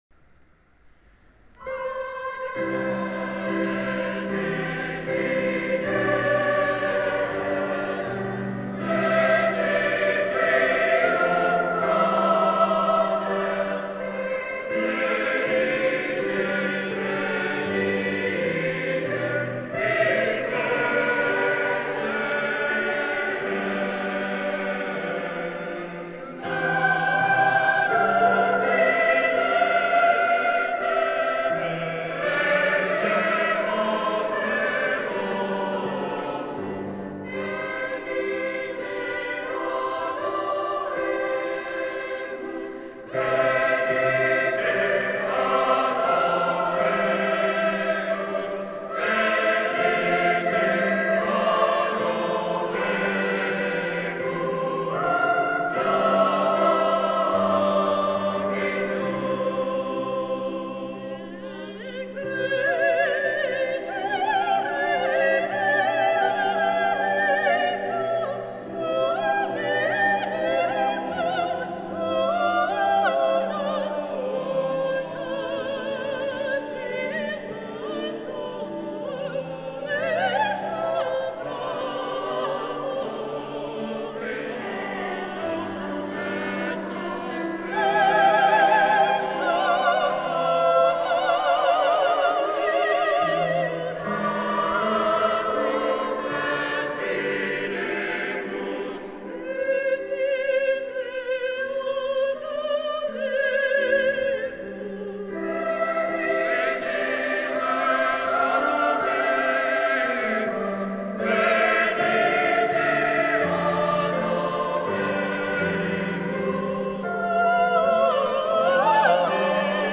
Nom du t�l�chargement : Concert de no�l 2012 : extrait du chant.